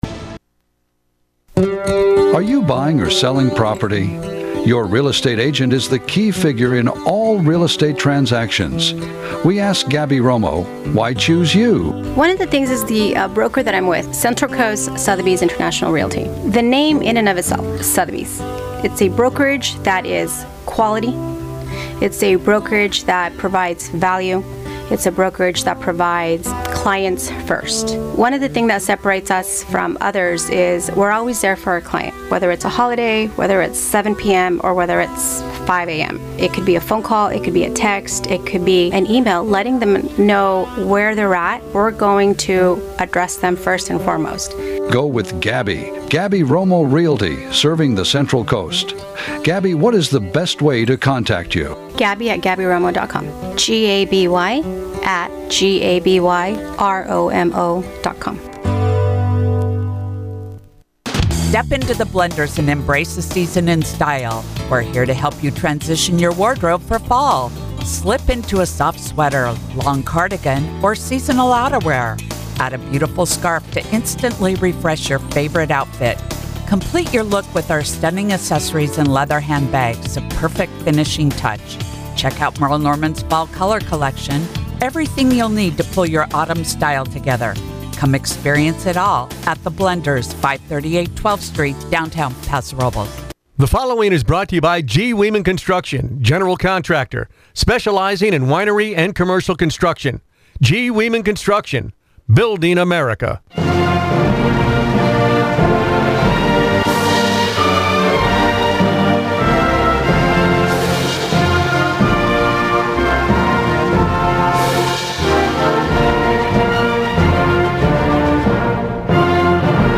The Morning Exchange; North County’s local news show airs 6 a.m. to 9 a.m. every weekday.
Weather every hour around the clock. Call in and let your voice be heard on a number of topics.